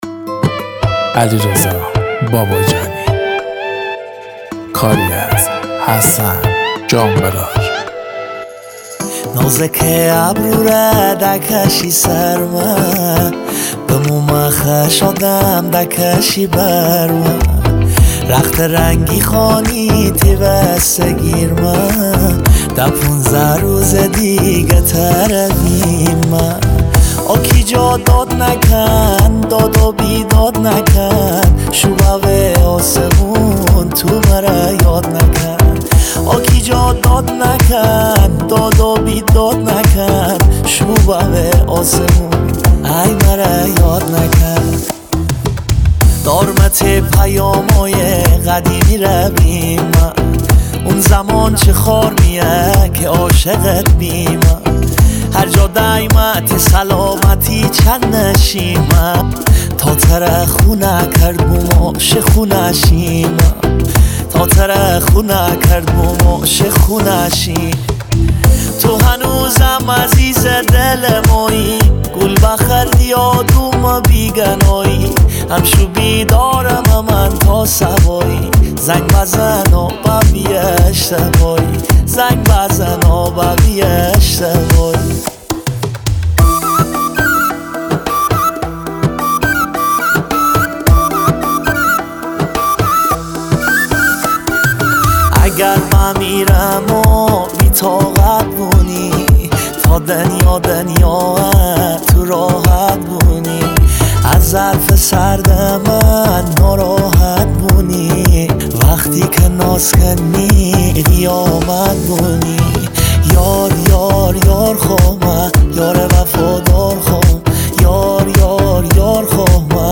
با صدای خواننده خوش صدای شمالی
آهنگی در سبک آهنگ های ریمیکس مازندرانی